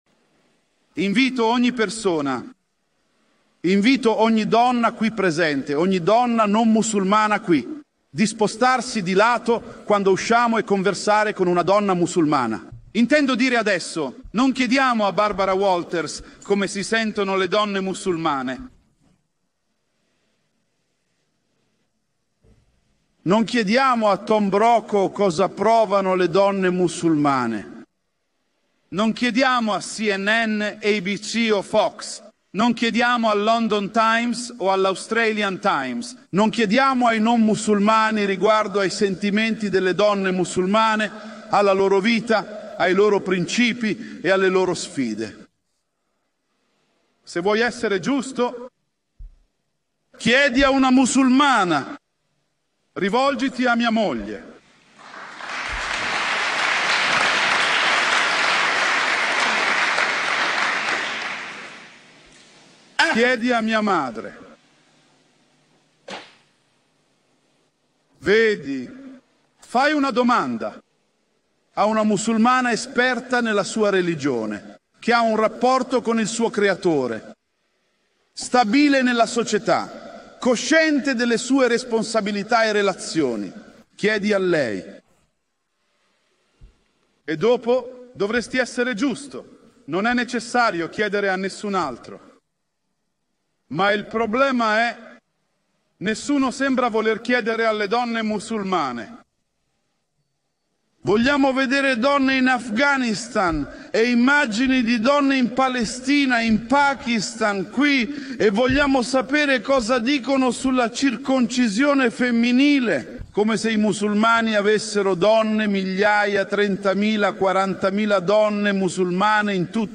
Il video è un bellissimo estratto da una delle lezioni